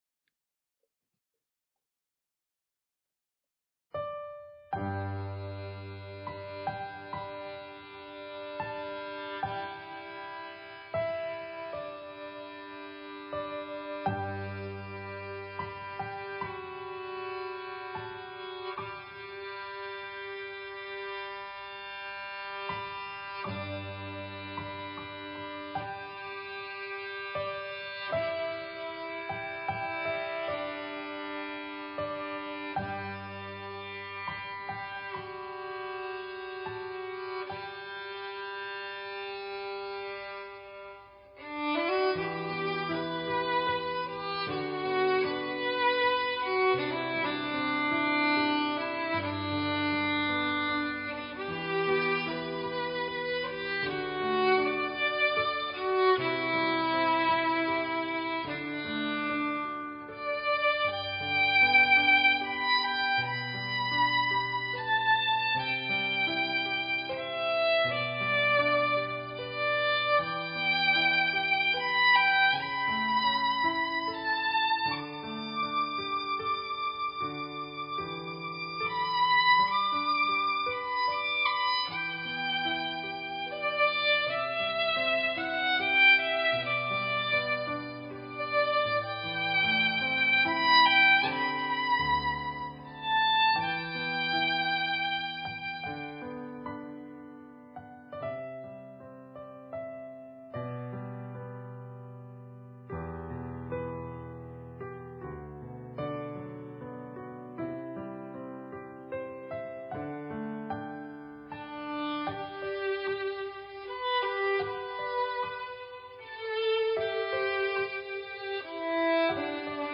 A broken chord accompaniment supports the violin melody throughout.
It is set in the key of G, and then modulates up to the key of A. It opens with the violin playing double stops. These are open string double stops using the G string as a drone.
The bow stroke used through the whole piece is legato detaché.
Amazing Grace – Violin Solo